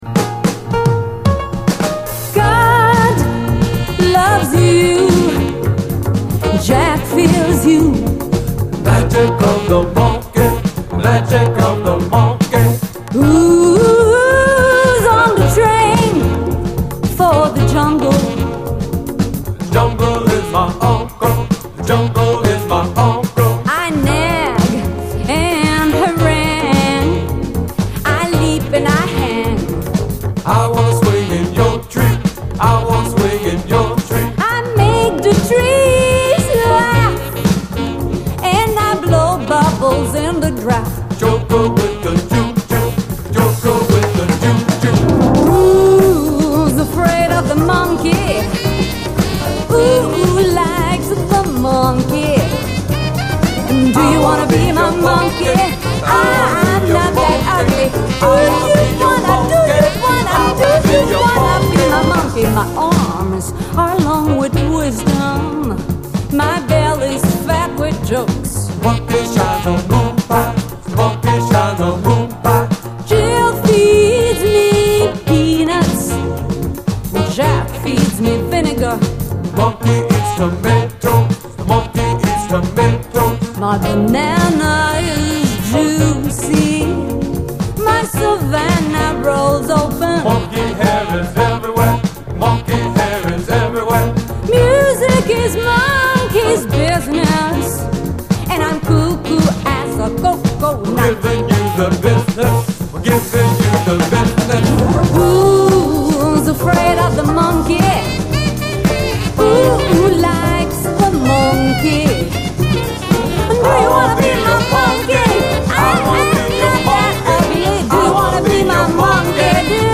ニューオーリンズ産ポエトリー・ジャズ！
黒人女性シンガーをフィーチャーしたメロウ・ジャジー・ソウル
ジャジーであると同時にリズムはセカンド・ライン調
イカれたシンセが飛び交うニューオーリンズ・ファンク・トラック上で延々ポエトリーを叫び続ける変化球ファンキー・ソウル！